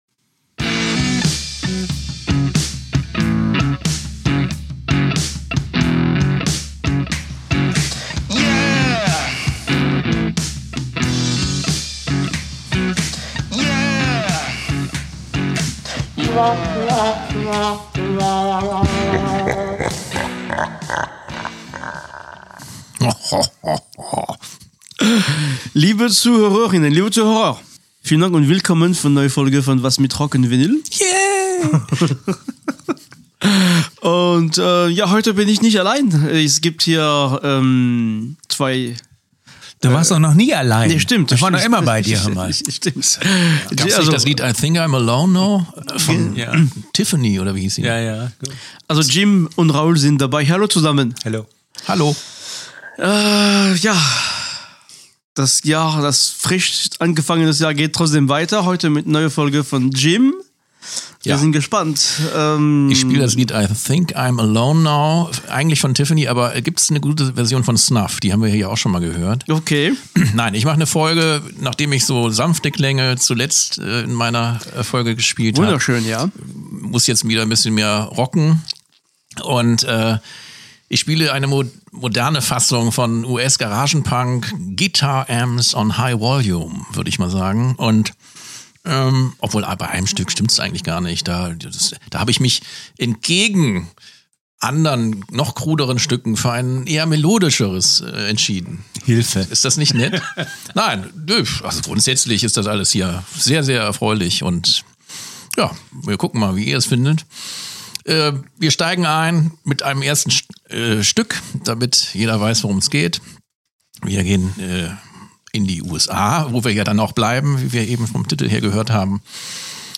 #213 Turn up the amps: US Garage Punk from the early 90´s
213-turn-up-the-amps-us-garage-punk-from-the-early-90s-mmp.mp3